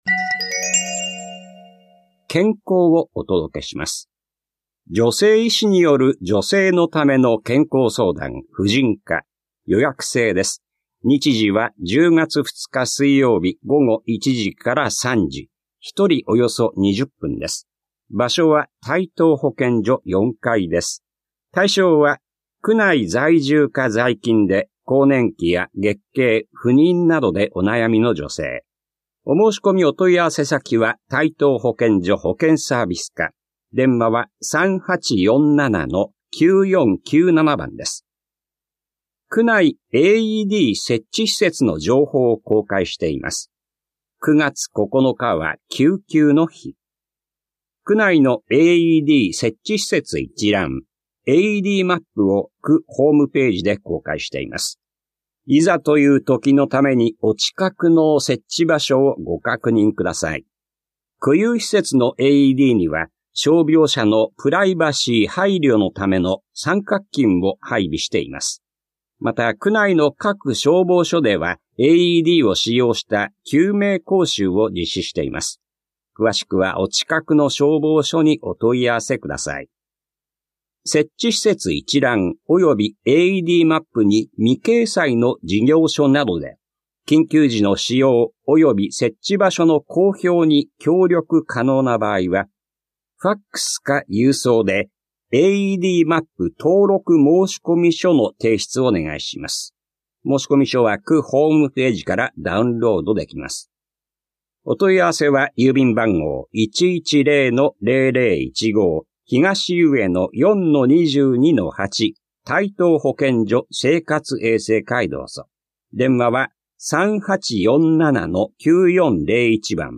広報「たいとう」令和6年9月5日号の音声読み上げデータです。